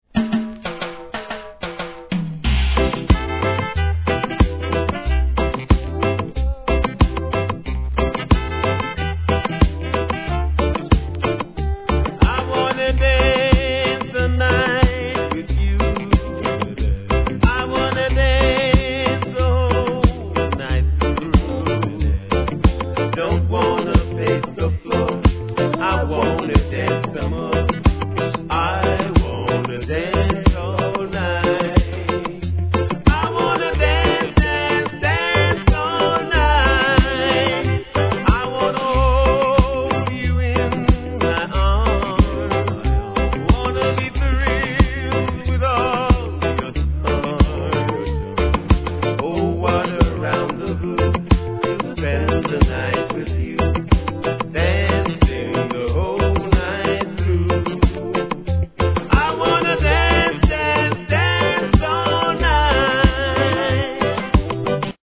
REGGAE
センセーショナルなヴォーカルで歌い上げた要クリック作品!